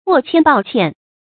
握鉛抱槧 注音： ㄨㄛˋ ㄑㄧㄢ ㄅㄠˋ ㄑㄧㄢˋ 讀音讀法： 意思解釋： 鉛，鉛粉；槧，木簡。